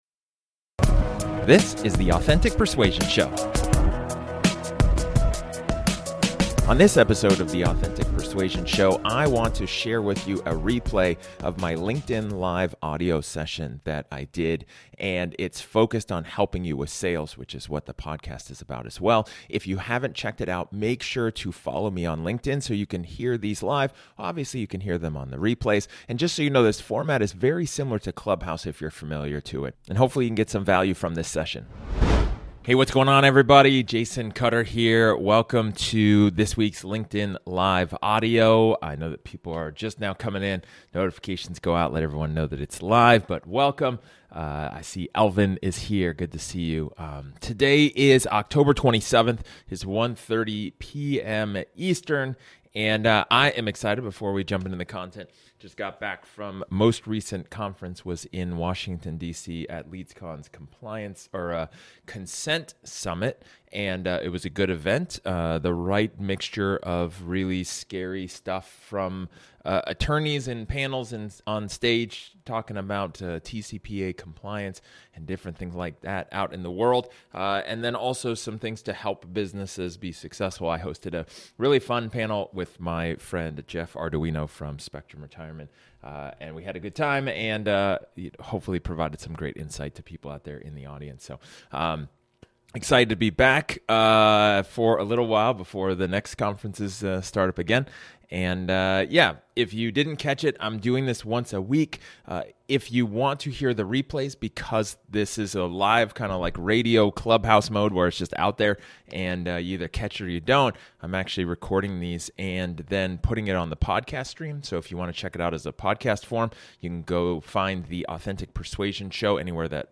What if the deals you have with clients are all one-call close? In this special episode, you will be listening to a replay of my LinkedIn Live Audio a few weeks ago. Given that the sales industry is very much uniform in its belief that it is a challenging game, this is absolutely an interesting discussion to explore.